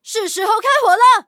LT-35夜战攻击语音.OGG